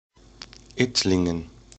English: Pronounciation of Ittlingen